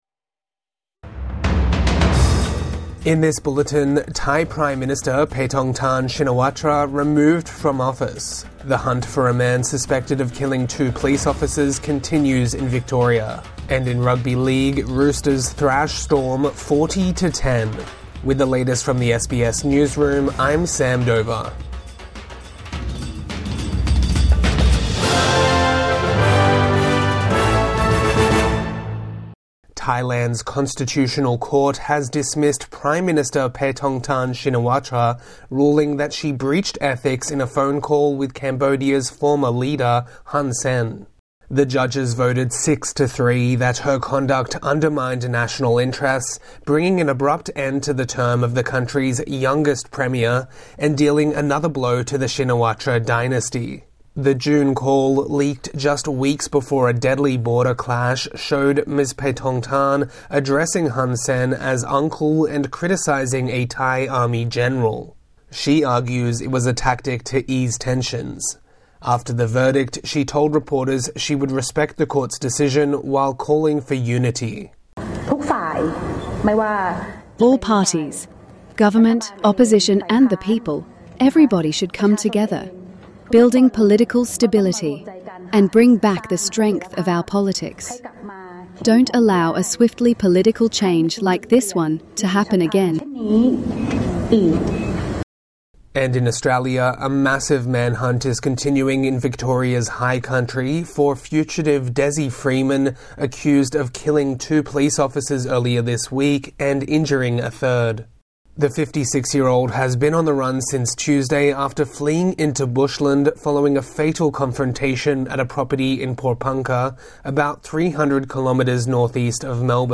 Thai Prime Minister dismissed over leaked phone call | Morning News Bulletin 30 August 2025